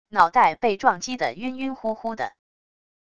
脑袋被撞击的晕晕乎乎的wav音频